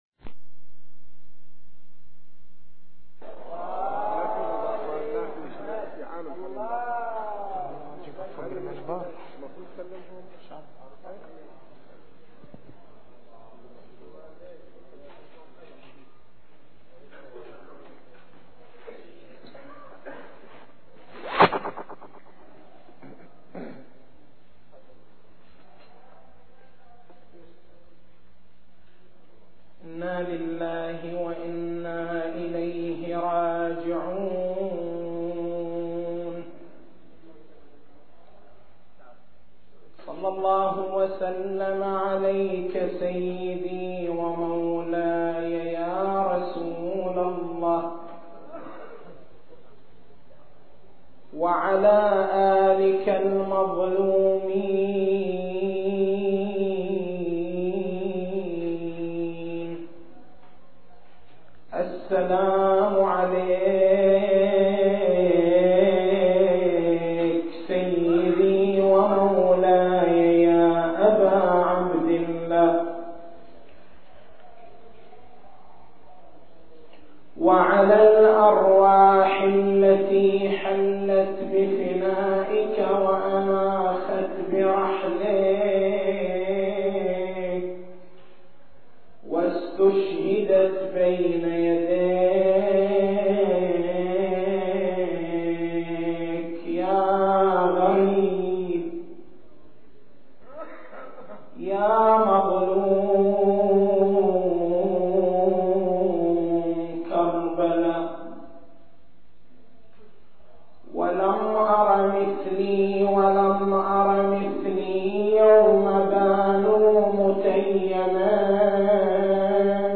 تاريخ المحاضرة: 03/01/1425 نقاط البحث: معنى الأمة الوسط معنى مقام الشاهدية أهمية الاعتقاد بمقام الشاهدية التسجيل الصوتي: تحميل التسجيل الصوتي: شبكة الضياء > مكتبة المحاضرات > محرم الحرام > محرم الحرام 1425